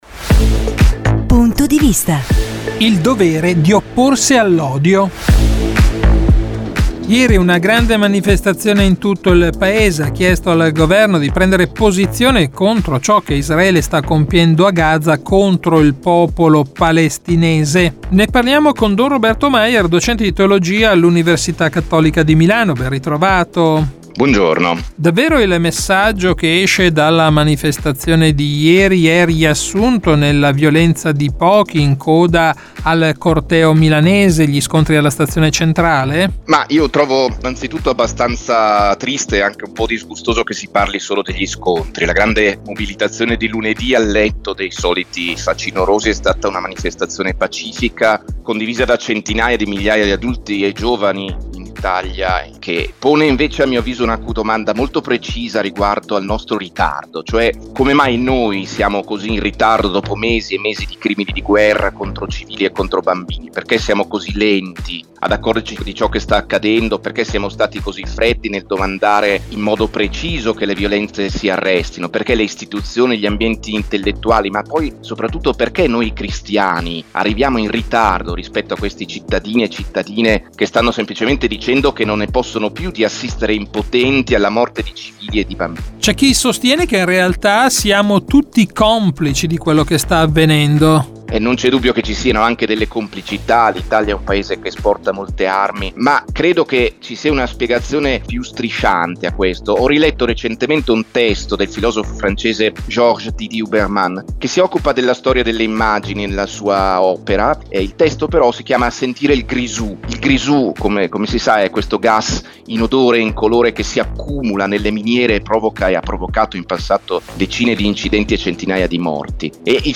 Un dialogo